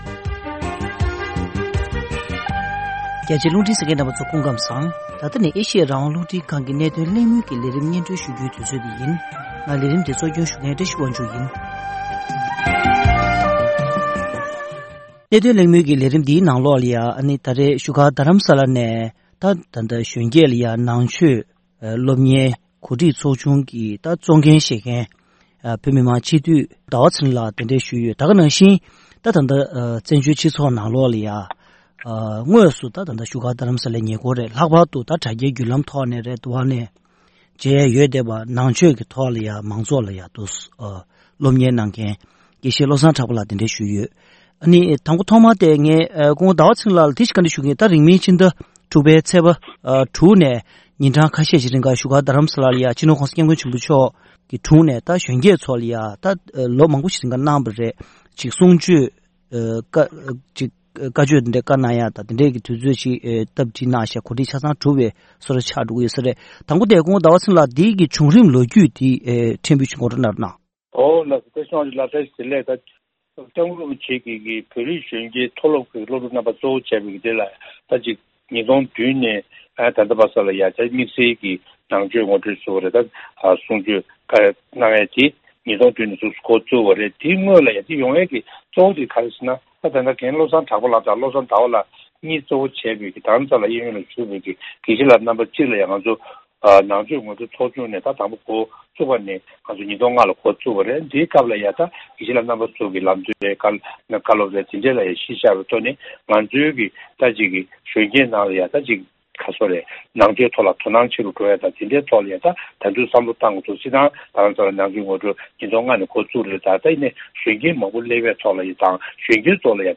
༧གོང་ས་མཆོག་ནས་བཞུགས་སྒར་དུ་བོད་རིགས་གཞོན་སྐྱེས་ཚོར་བསྩལ་རྒྱུའི་ནང་ཆོས་ངོ་སྤྲོད་ཀྱི་ལས་རིམ་དང་འབྲེལ་བའི་སྐོར་གླེང་མོལ་ཞུས་པ།